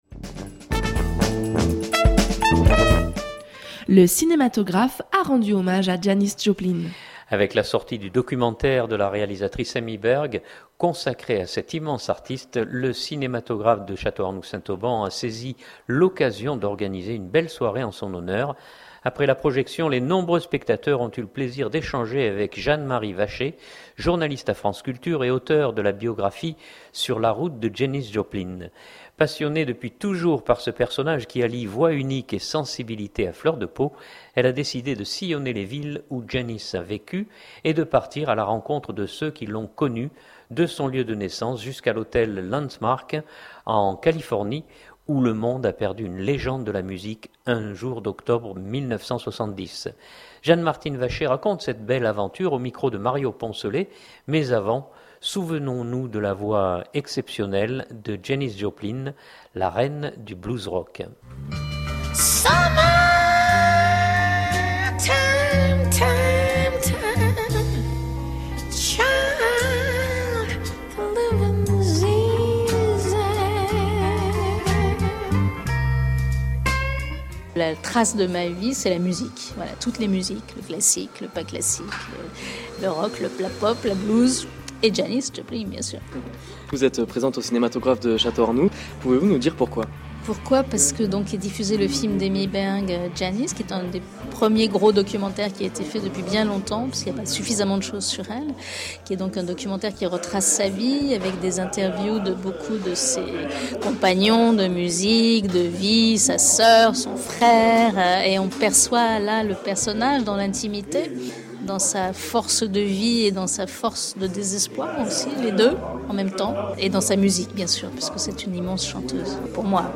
Avec la sortie du documentaire de la réalisatrice Amy Berg consacré à cette immense artiste, le Cinématographe a saisi l’occasion d’organiser une belle soirée en son honneur.